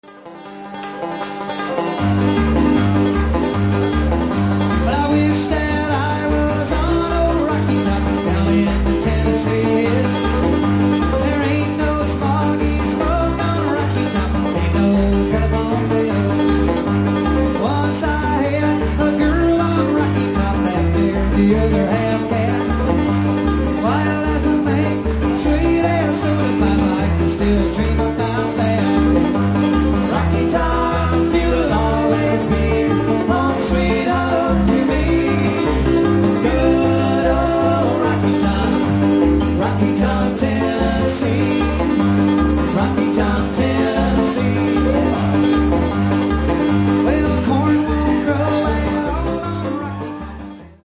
guitar and banjo
including some live recordings